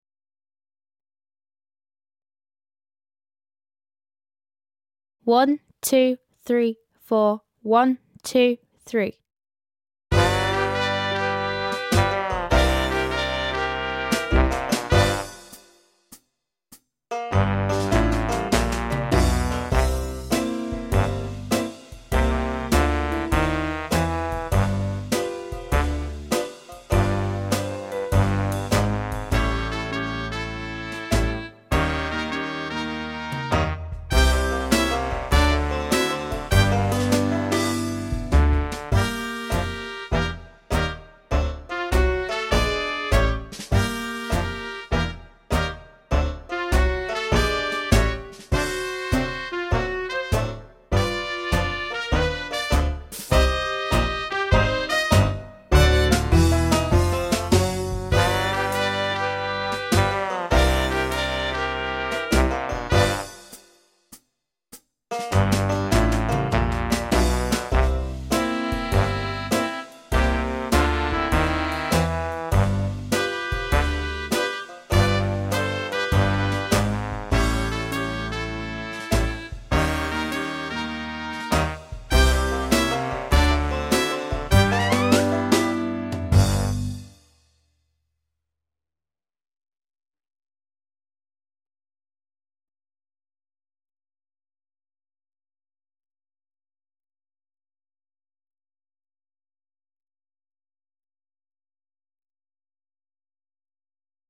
33 Haley's Comet (Backing Track)